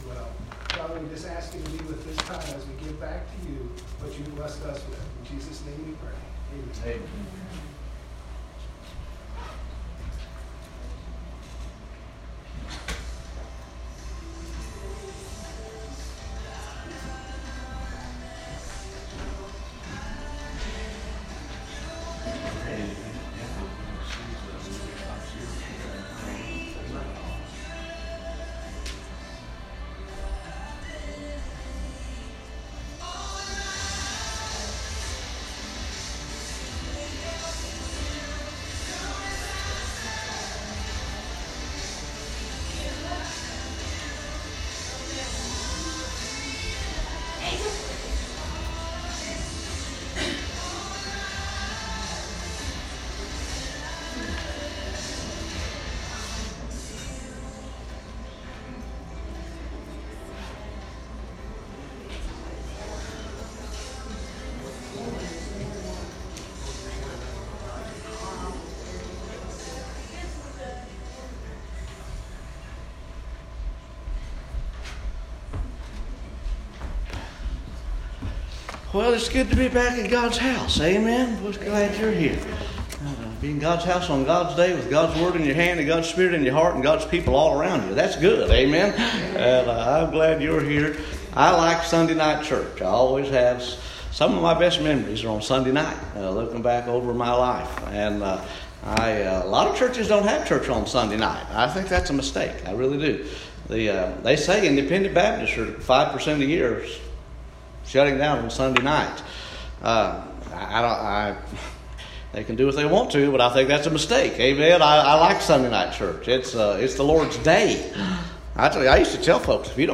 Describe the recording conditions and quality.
Part 2 of our Fall revival meeting 2019.